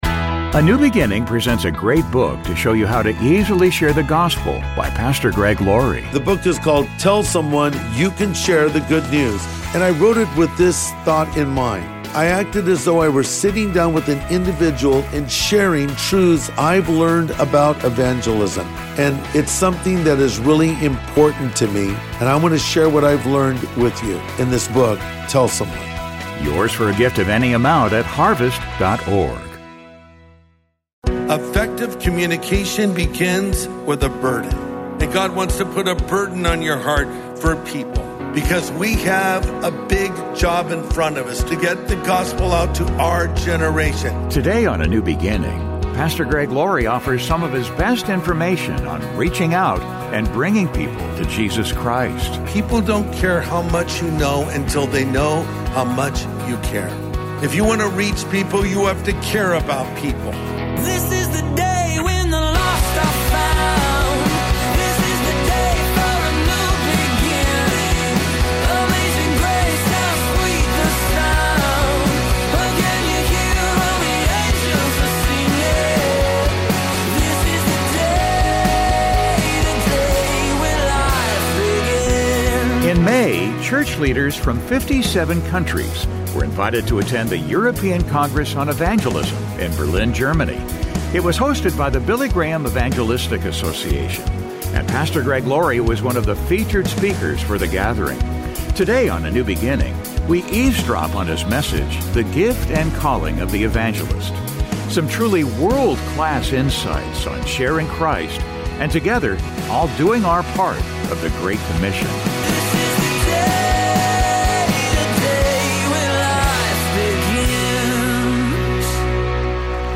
In May, church leaders from 57 countries were invited to attend the European CongressÂ on Evangelism in Berlin, Germany.
And Pastor Greg Laurie was one of the featured speakers for theÂ gathering.